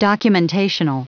Prononciation du mot documentational en anglais (fichier audio)
Prononciation du mot : documentational
documentational.wav